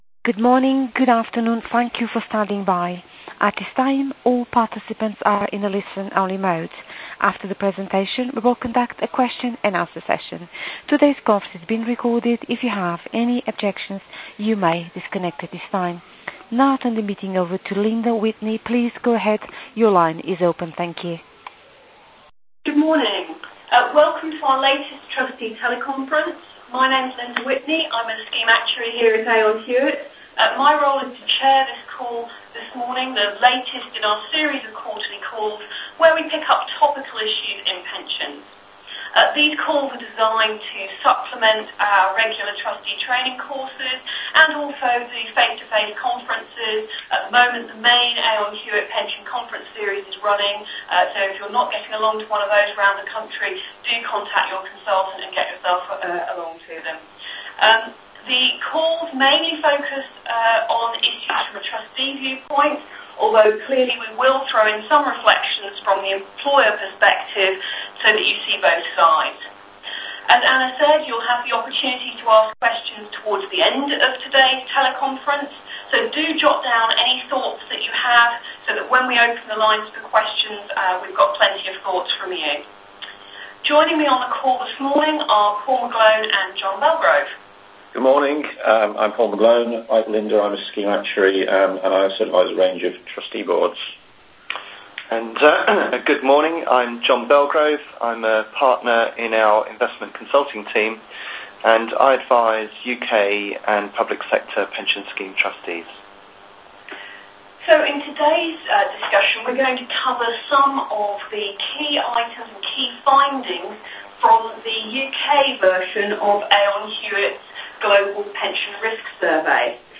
Recorded Teleconference: Topical Trustee Issues ¦ United Kingdom
At the teleconference the panel discussed: